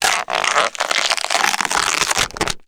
ALIEN_Insect_07_mono.wav